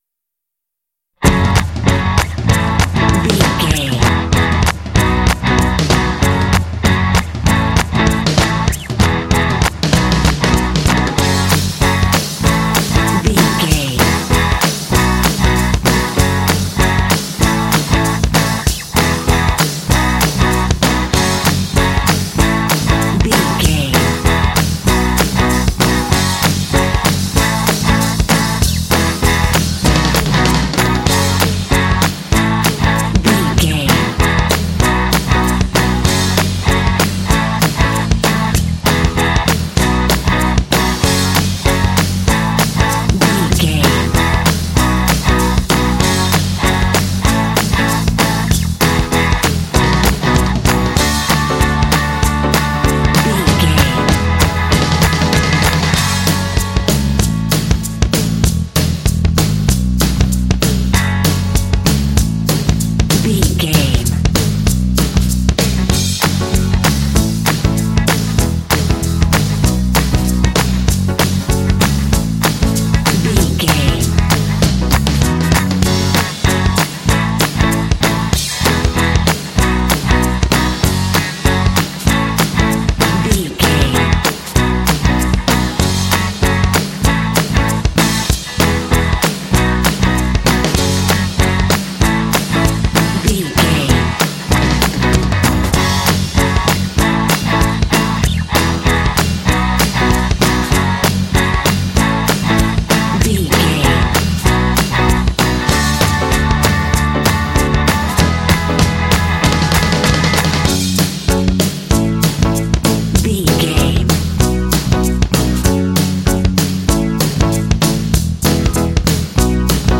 Uplifting
Aeolian/Minor
F#
energetic
lively
bass guitar
electric guitar
drums
percussion
rock
alternative rock